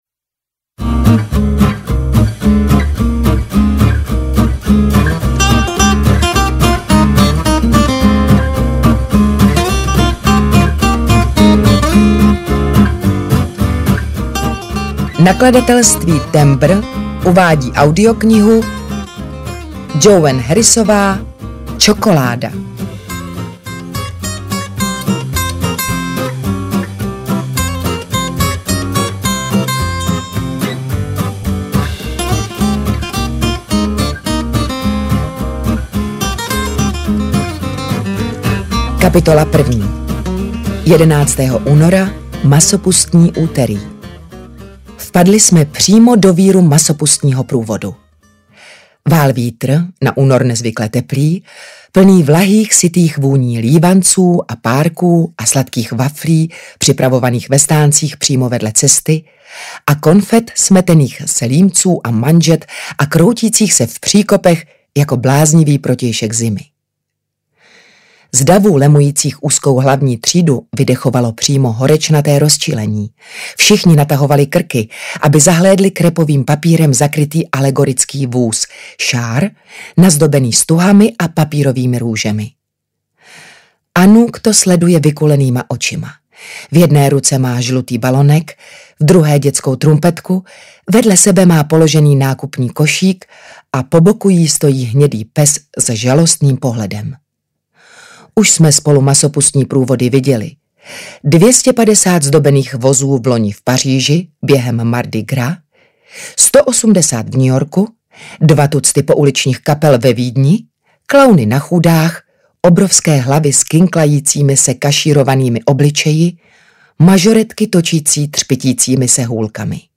Čokoláda audiokniha
Ukázka z knihy
cokolada-audiokniha